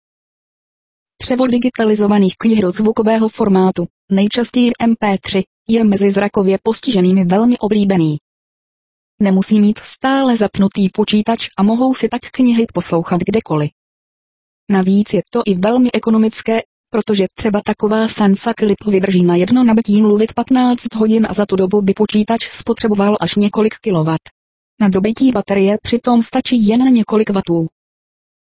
Lara a Julie od Linguatec. Součástí programu Voice Reader je i převod textu do zvukových formátů MP3 a WAV.
larasan.mp3